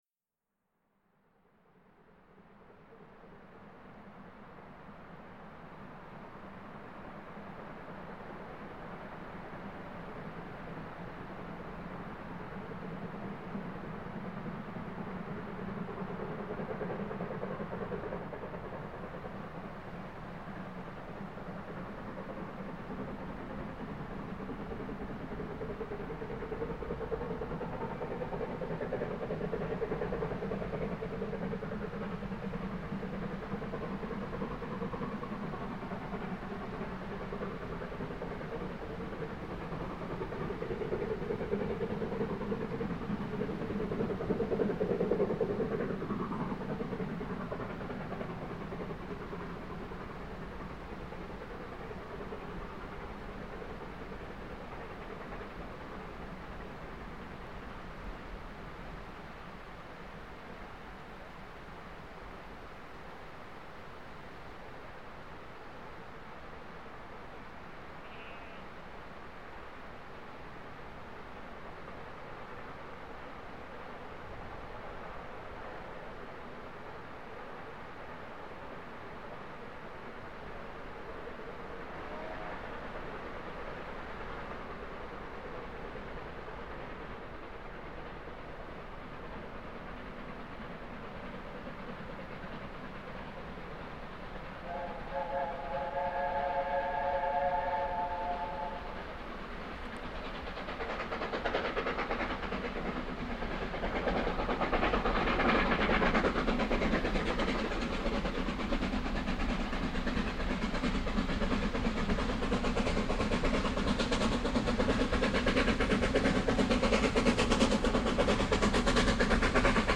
Genau an dieser Stelle wurde das damalige Paradepferd auf der NYMR akustisch aufgenommen, die Lok 60007, stromlinienverkleidete ex LNER-A4-Pacifik.
Akustisch wie optisch ist diese Lokbauart für kontinentale Eisenbahnfreunde allerdings stark gewöhnungsbedürftig.
60007 in der Darnholme-Kurve kurz vor Goathland, am 04.08.2000 um 17:07h.